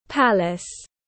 Palace /ˈpæl.ɪs/